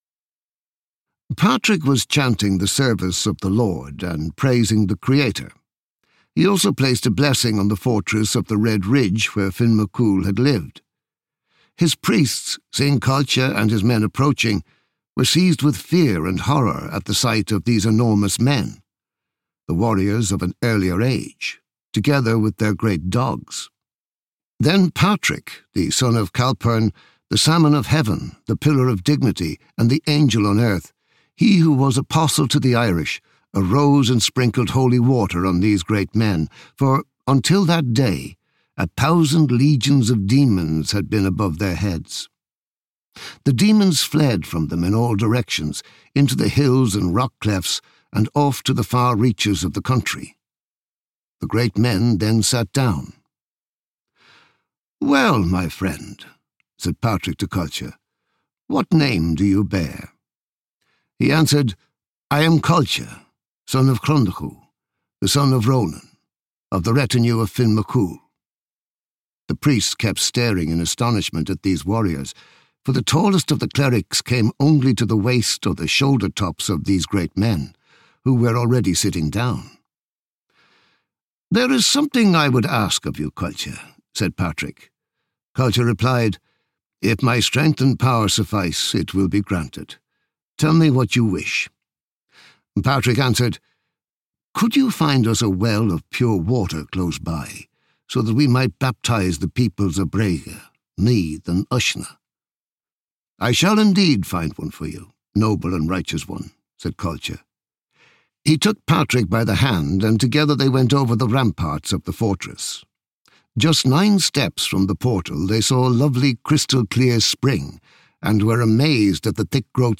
Audio knihaTales of the Elders of Ireland
Ukázka z knihy